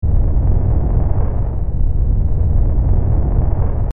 環境音 | 無料 BGM・効果音のフリー音源素材 | Springin’ Sound Stock
地響き1ループ.mp3